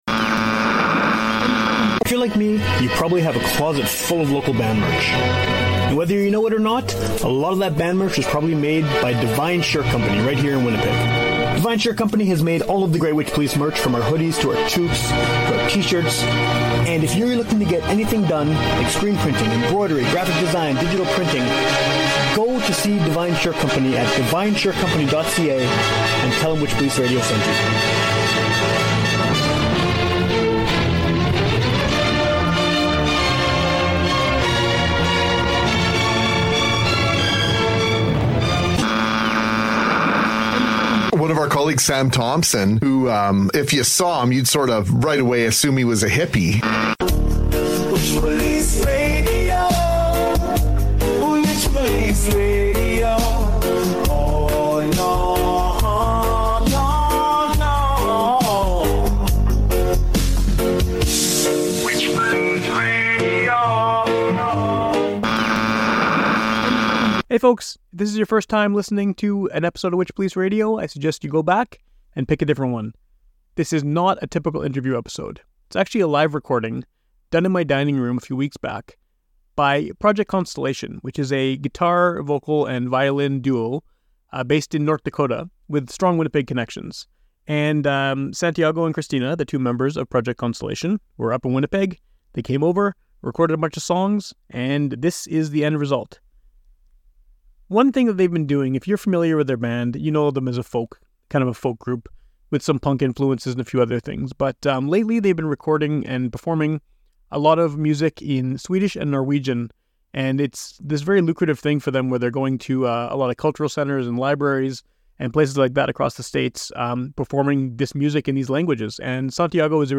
husband-and-wife folk duo
Scandinavian songs
played some stuff at my house